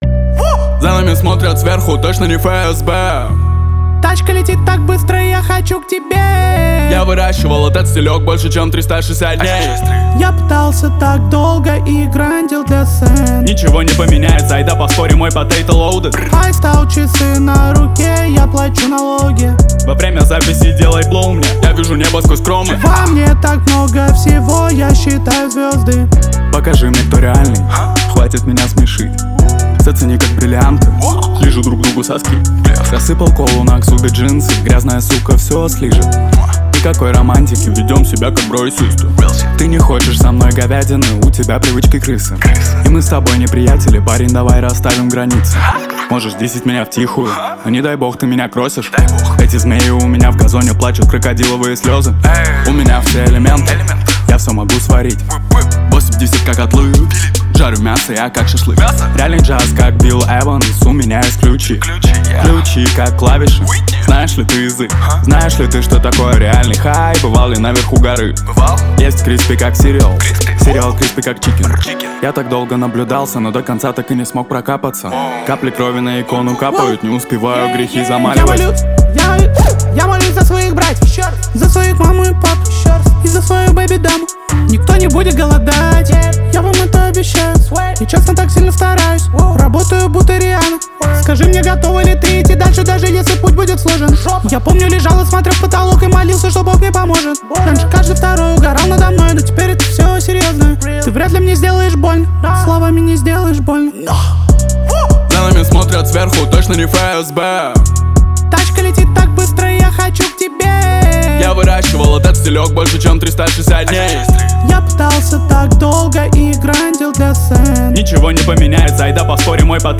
Новинки, Рэп